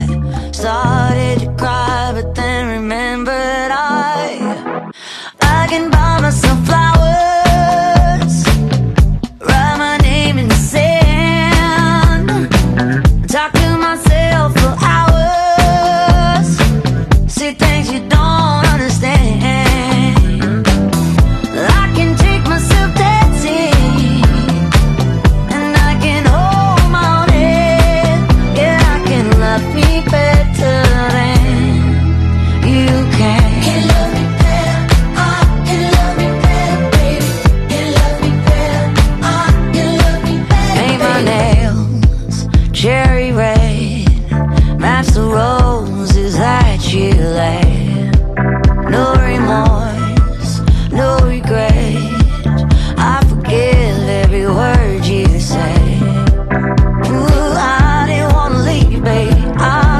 Unveiled Roots Daily Tarot Reading sound effects free download